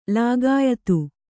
♪ lāgāyatu